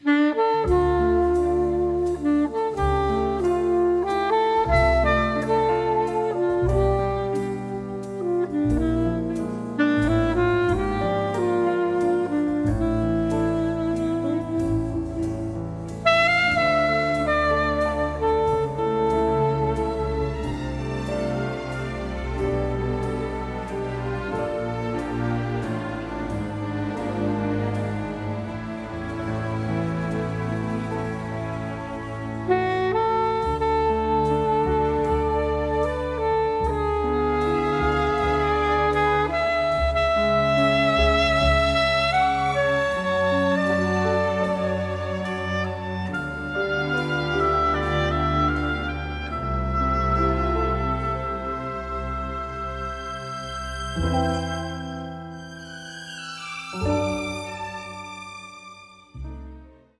noir score
beautiful noir theme for saxophone
record the music in Paris
saxophonist